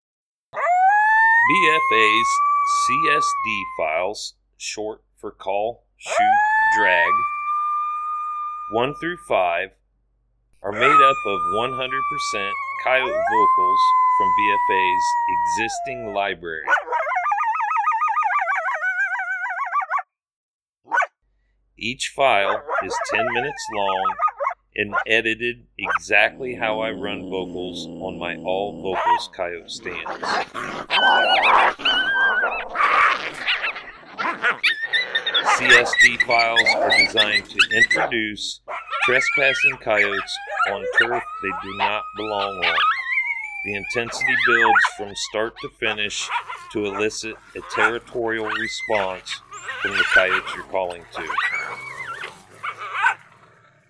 Each BFA CSD File is 10 minutes in length, made up from our most popular Coyote Howls, Coyote Social Vocalizations and Coyote fights.